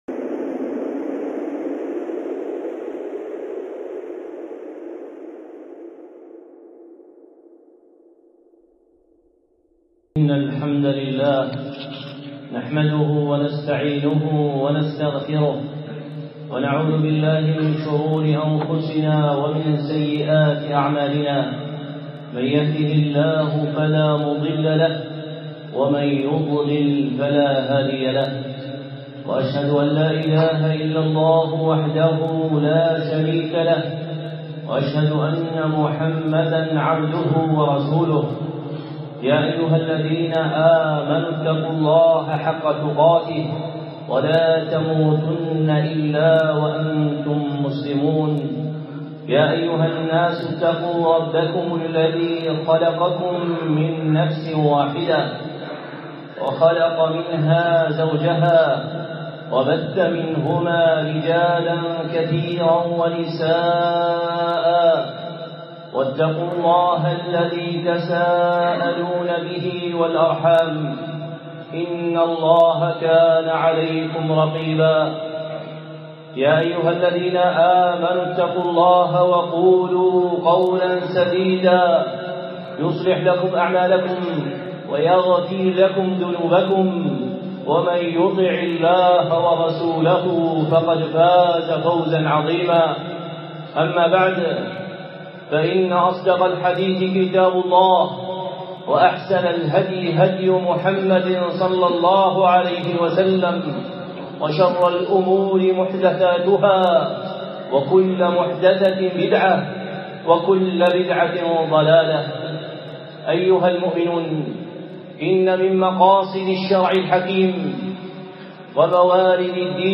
الخطب المنبرية